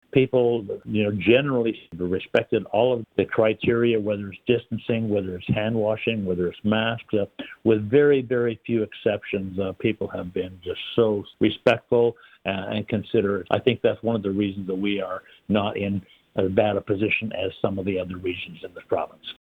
Kramp weighed in on the pandemic and more during a recent year in review interview with Quinte News.